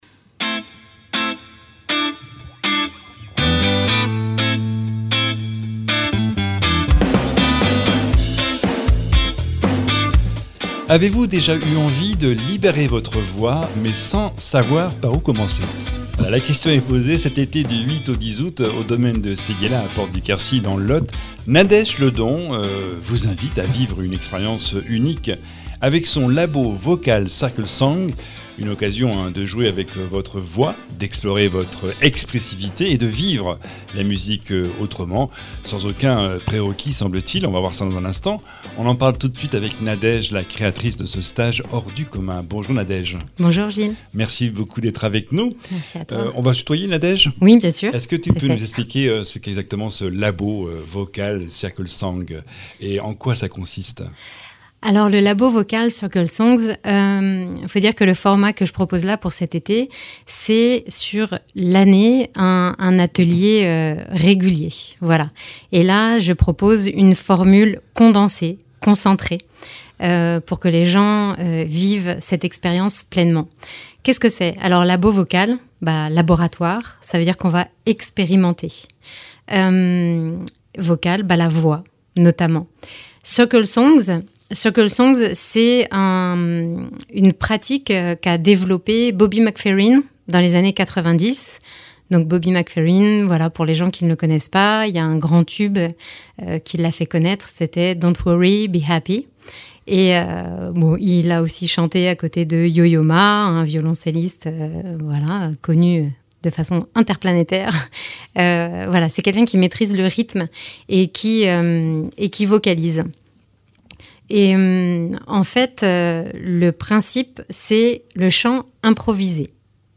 auteure-compositrice-interprète.